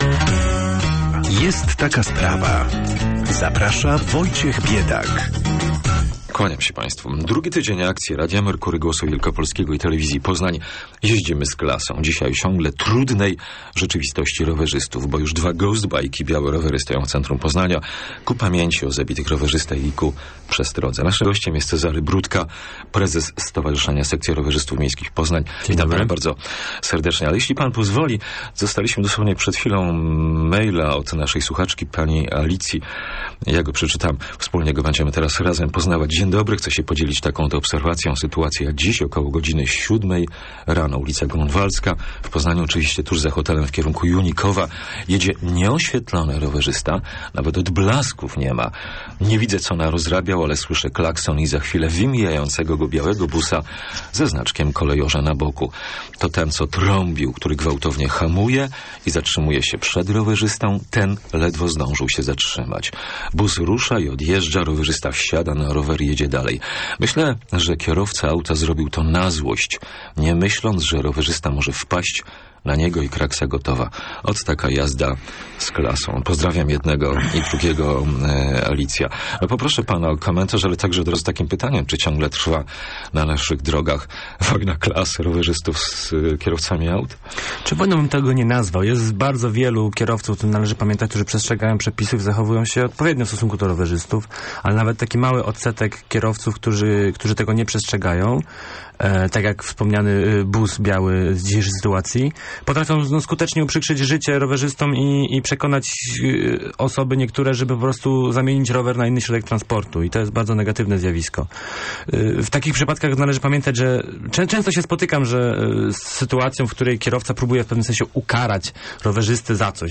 - napisała w e-mailu Słuchaczka, tuż przed antenową rozmową z porannym gościem.